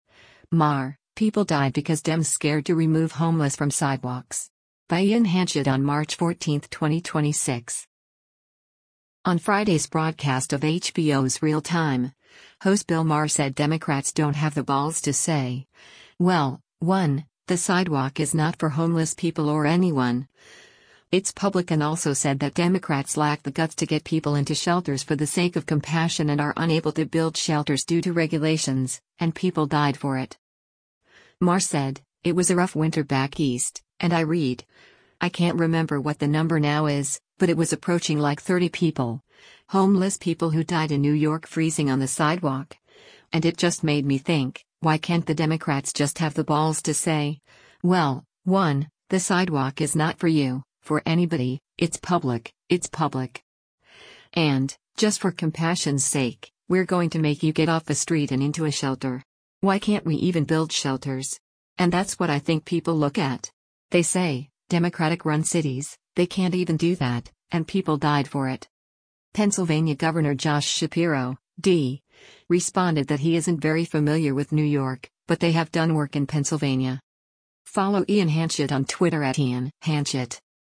On Friday’s broadcast of HBO’s “Real Time,” host Bill Maher said Democrats don’t “have the balls to say, well, one, the sidewalk is not for” homeless people or anyone, it’s public and also said that Democrats lack the guts to get people into shelters for the sake of compassion and are unable to build shelters due to regulations, “and people died for it.”
Pennsylvania Gov. Josh Shapiro (D) responded that he isn’t very familiar with New York, but they have done work in Pennsylvania.